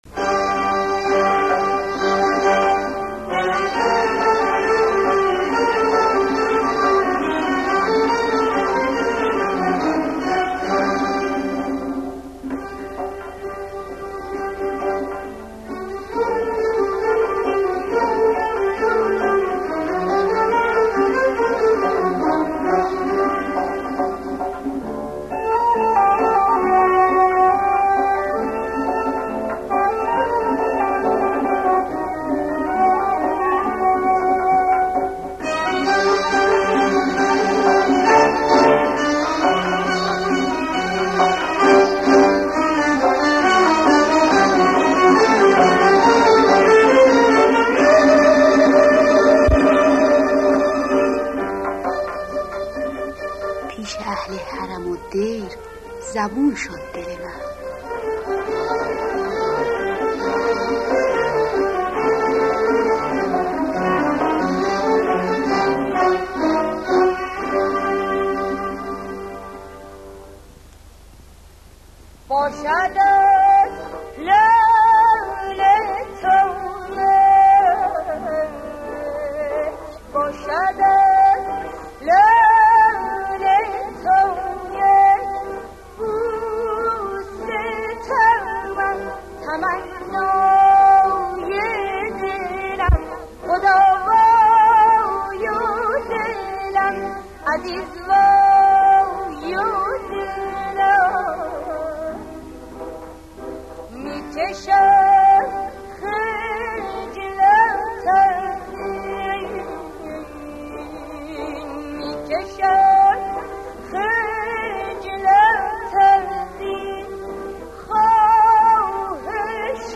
دستگاه: اصفهان